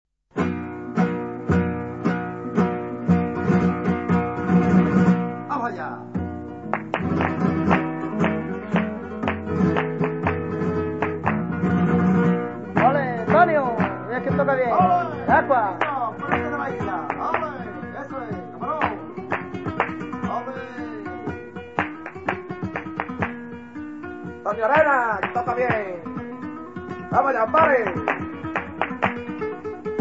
Soleares del Puerto